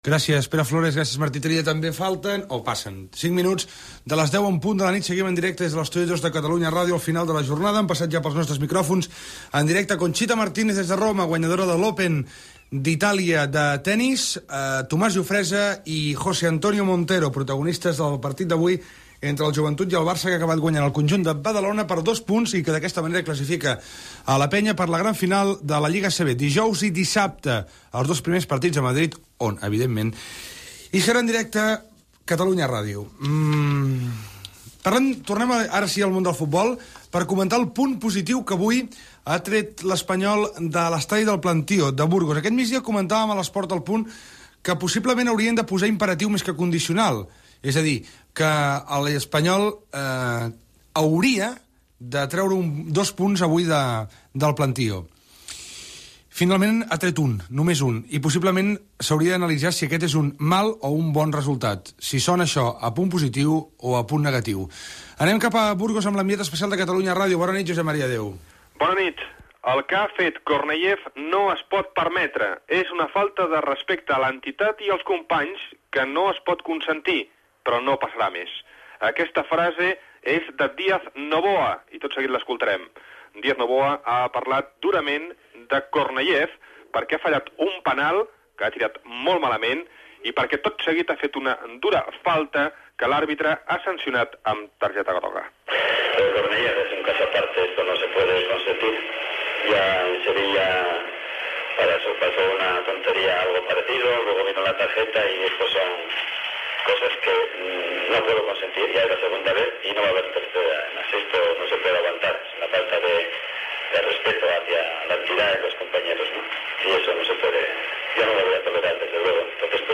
8ccbeab98c5093e6b583bc88aa63eb1d1807d910.mp3 Títol Catalunya Ràdio Emissora Catalunya Ràdio Cadena Catalunya Ràdio Titularitat Pública nacional Nom programa Al final de la jornada Descripció Hora, identificació del programa, esportistes entrevistats, crònica del partit de futbol masculí entre l'Espanyol i el Burgos al camp del Plantío, victòria de la Unió Esportiva Lleida, crònica des de Mèrida on la Unió Esportiva Figueres ha empatat.